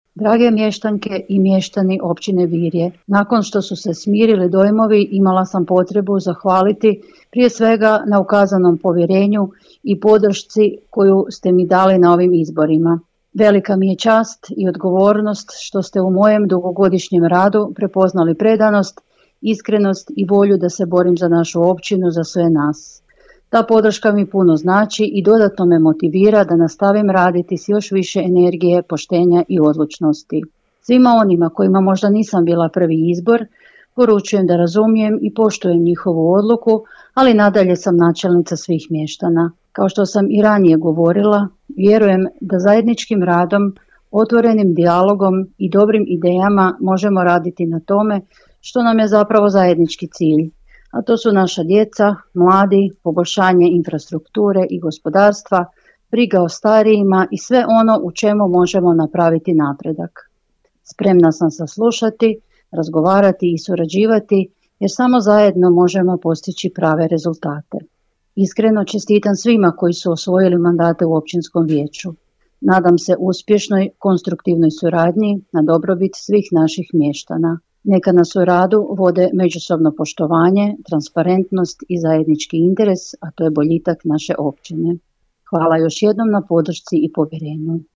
Načelnica Filipović podijelila je s nama dojmove nakon održanih izbora;